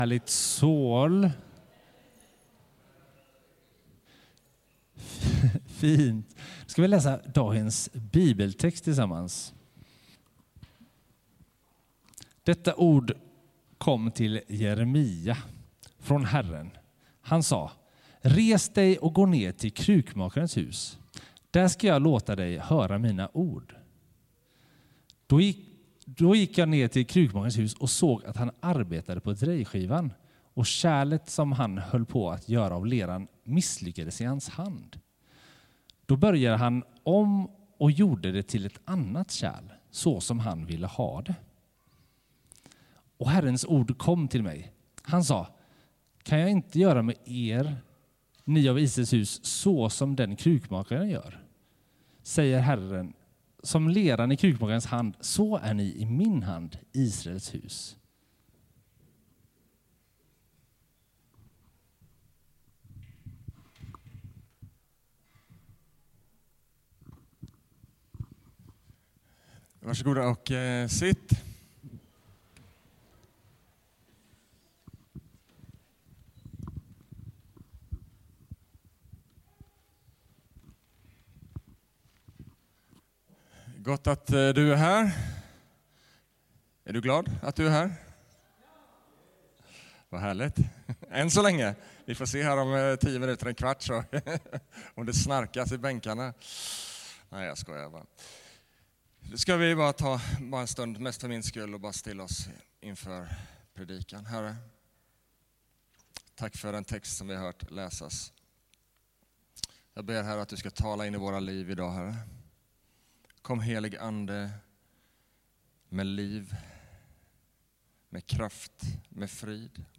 Jeremia | Sermon böcker | CENTRO